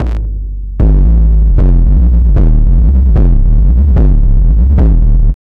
HD BD 17  -R.wav